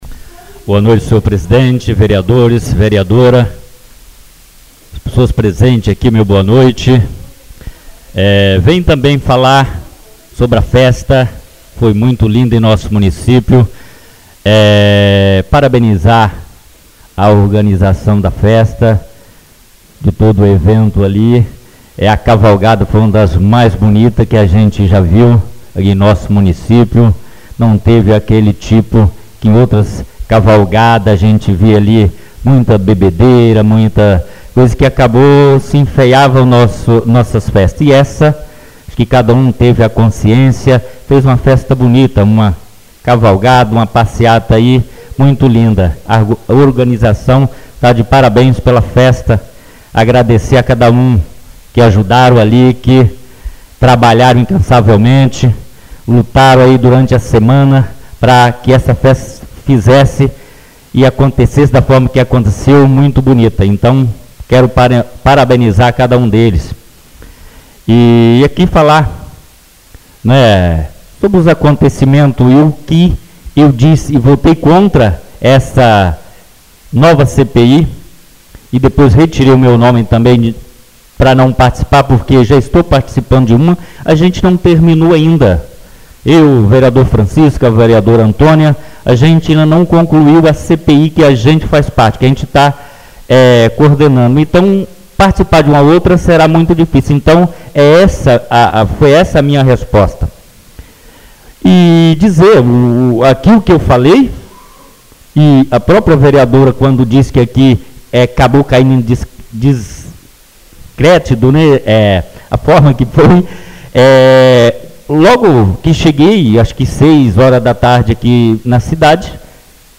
Oradores das Explicações Pessoais (27ª Ordinária da 3ª Sessão Legislativa da 6ª Legislatura)